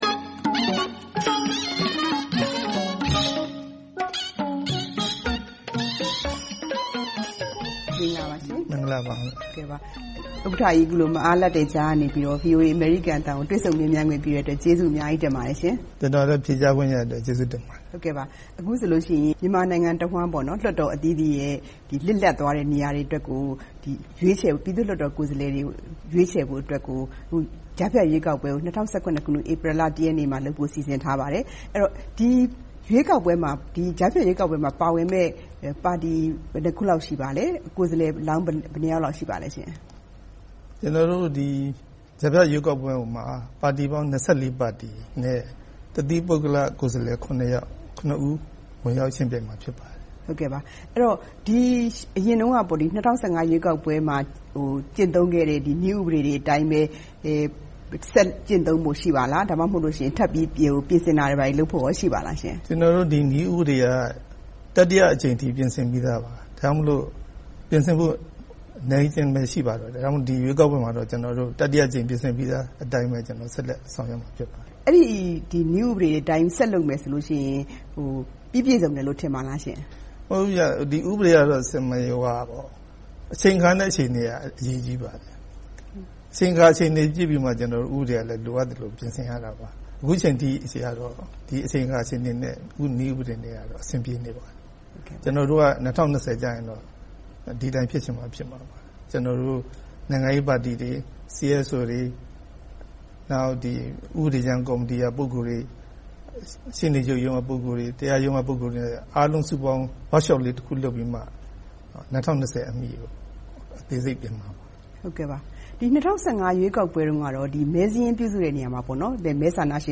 နေပြည်တော်မှာ သီးသန့်တွေ့ဆုံမေးမြန်းထားပါတယ်။